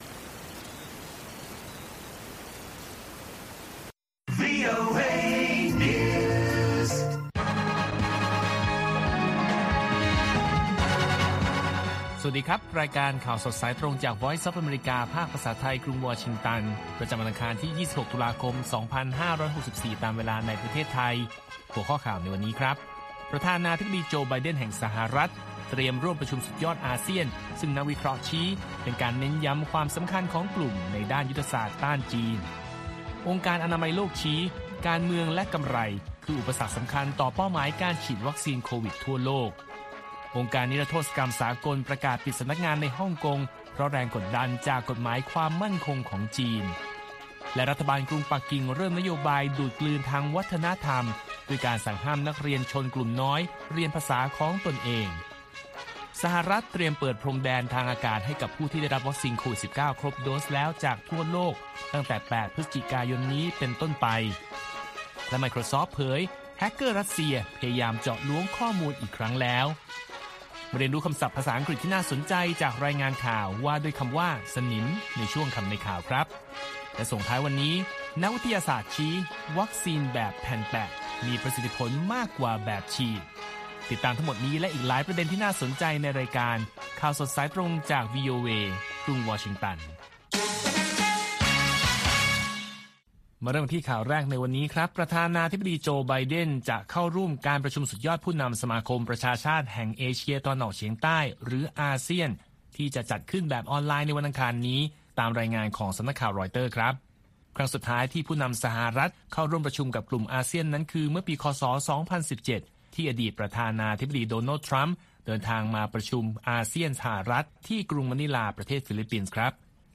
ข่าวสดสายตรงจากวีโอเอ ภาคภาษาไทย ประจำวันอังคารที่ 26 ตุลาคม 2564 ตามเวลาประเทศไทย